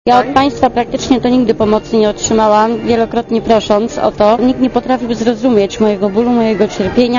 Komentarz audio
Pomoc państwa nigdy nie będzie wystarczająca - mówi szef resortu spraw wewnętrznych i administracji Ryszard Kalisz. - Wdowom po policjantach i ich dzieciom dajemy tyle, na ile nas stać